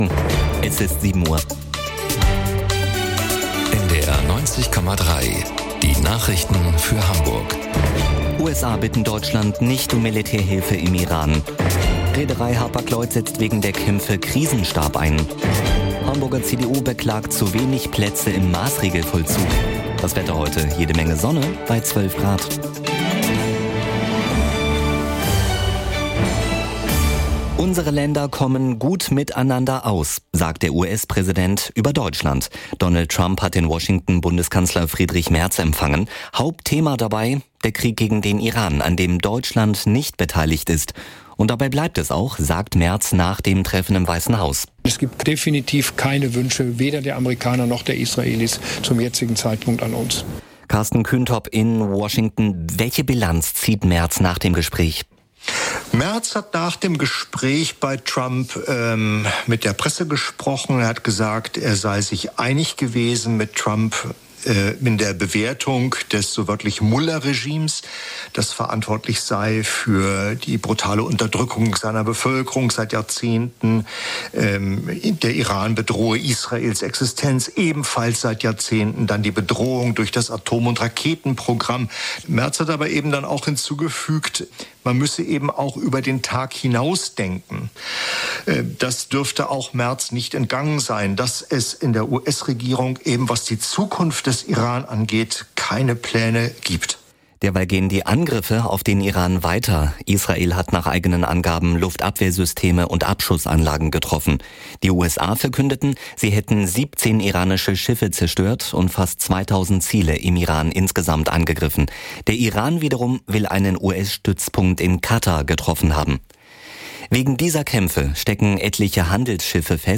Aktuelle Ereignisse, umfassende Informationen: Im Nachrichten-Podcast von NDR 90,3 hören Sie das Neueste aus Hamburg und der Welt.